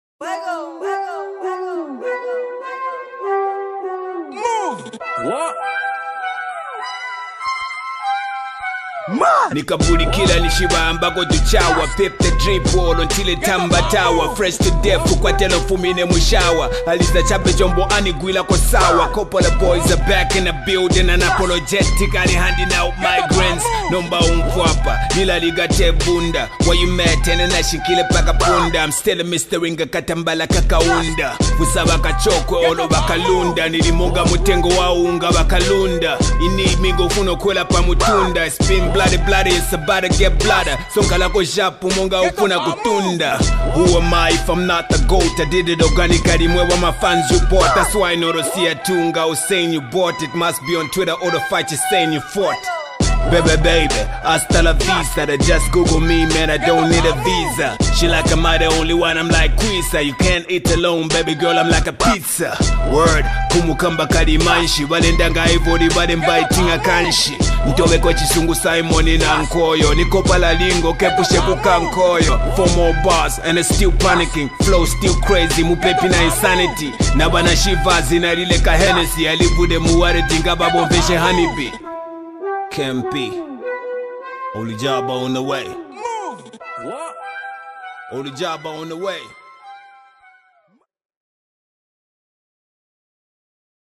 hot dope freestyle
show his rap skills in this freestyle